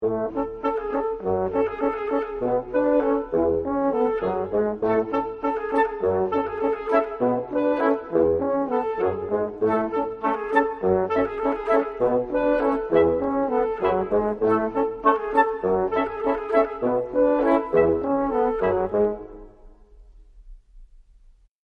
Звук радостной оркестровой мелодии